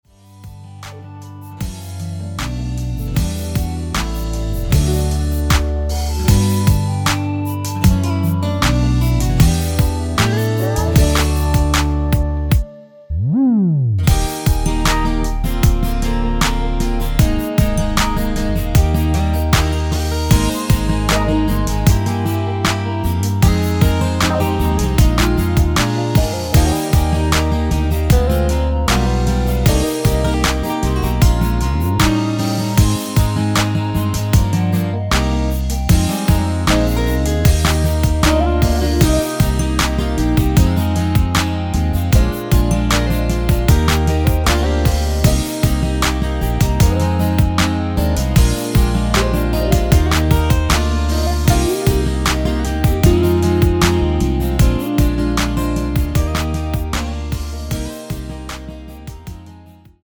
원키에서(-1)내린 멜로디 포함된 MR입니다.(미리듣기 확인)
D
앞부분30초, 뒷부분30초씩 편집해서 올려 드리고 있습니다.
중간에 음이 끈어지고 다시 나오는 이유는